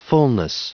Prononciation du mot fullness en anglais (fichier audio)
Prononciation du mot : fullness
fullness.wav